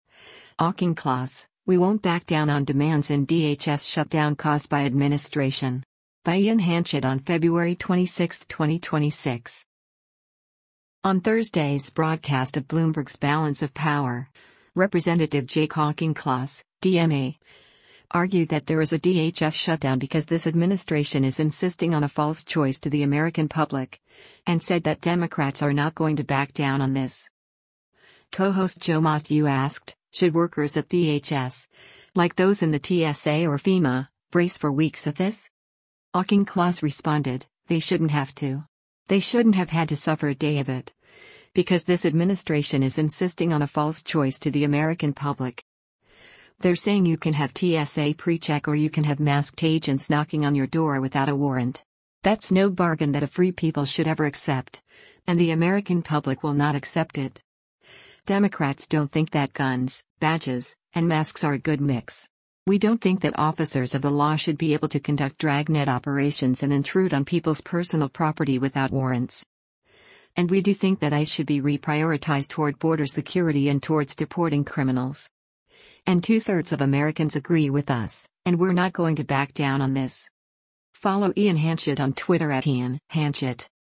On Thursday’s broadcast of Bloomberg’s “Balance of Power,” Rep. Jake Auchincloss (D-MA) argued that there is a DHS shutdown “because this administration is insisting on a false choice to the American public.”